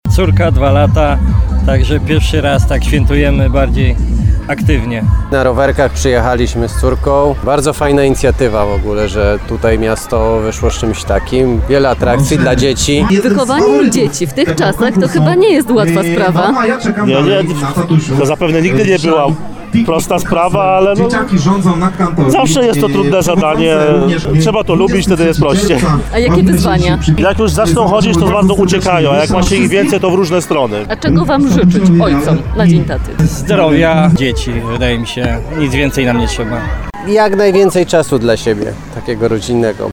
W Dniu Ojca odbył się tu piknik rodzinny, podczas którego to właśnie tatusiowie z dziećmi rządzili na Kantorii.